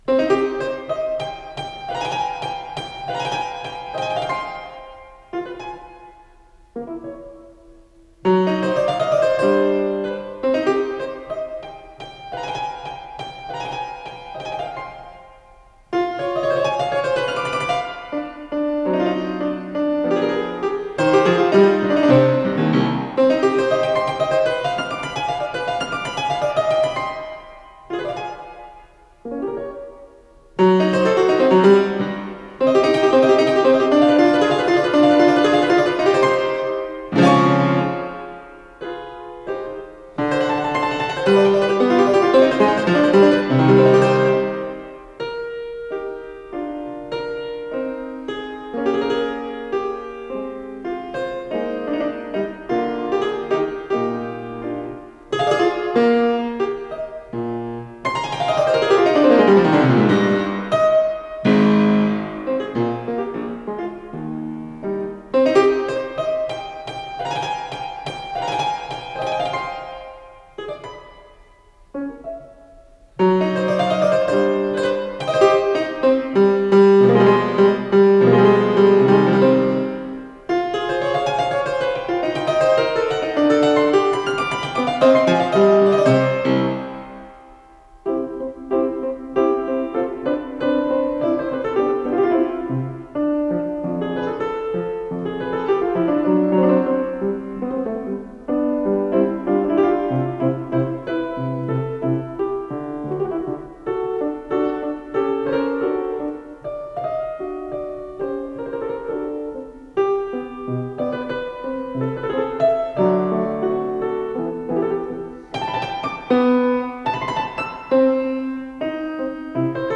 с профессиональной магнитной ленты
ПодзаголовокИз 5-ой тетради "Сонат, фантазиц и рондо для знатоков и любителей", соч. 1785, до минор
ИсполнителиАлексей Любимов - старинное молоточковое фортепиано
ВариантДубль моно